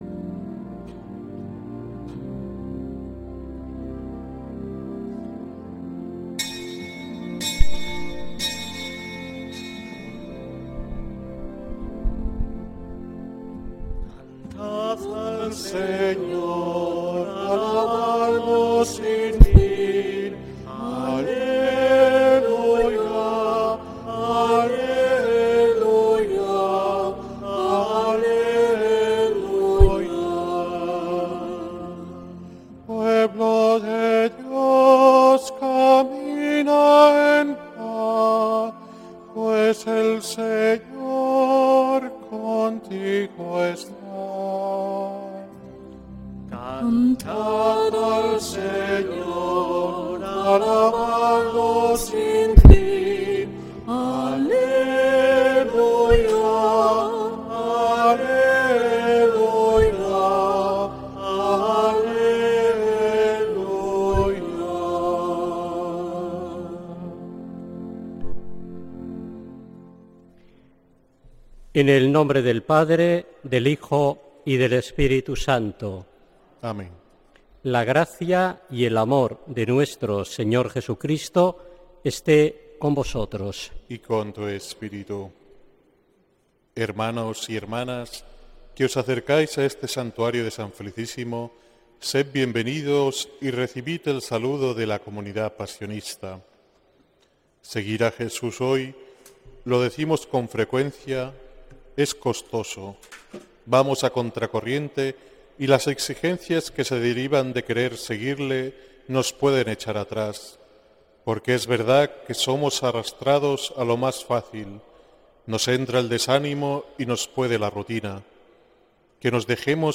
Santa Misa desde San Felicísimo en Deusto, domingo 24 de agosto de 2025